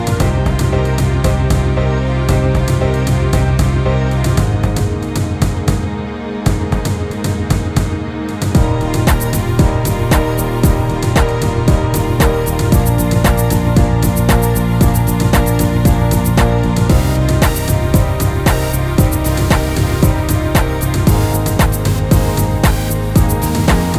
No Backing Vocals R'n'B / Hip Hop 4:21 Buy £1.50